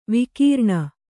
♪ vikīrṇa